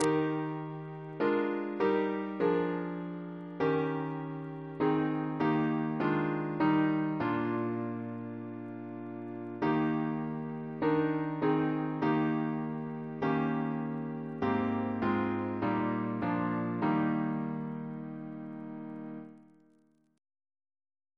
CCP: Chant sampler
Double chant in E♭ Composer: Joseph T. Harris (1745-1814) Reference psalters: ACP: 164; H1940: 696